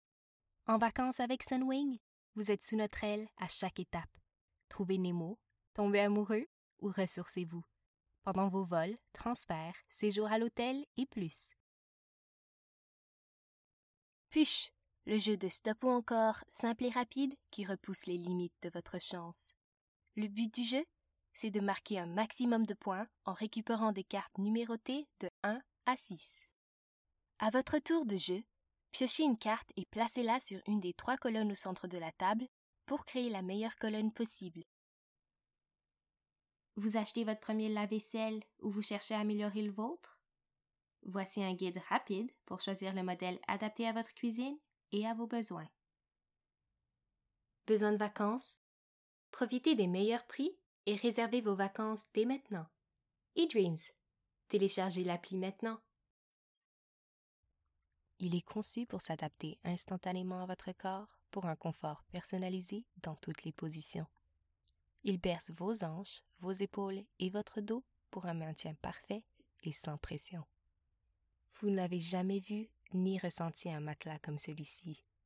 Commercials - FR